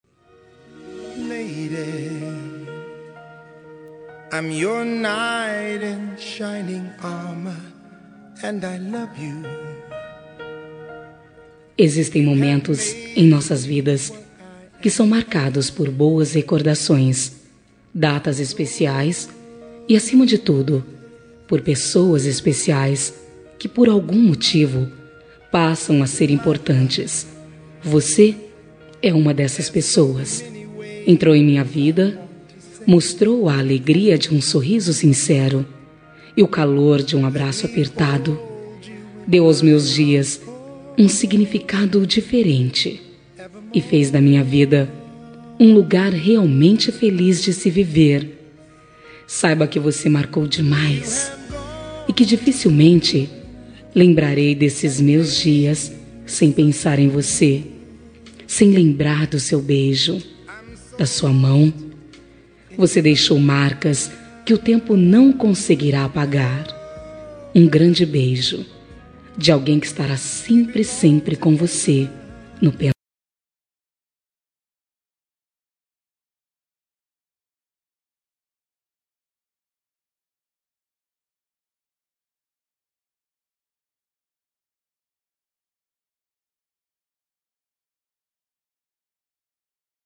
Telemensagem de Saudades – Voz Feminina – Cód: 230023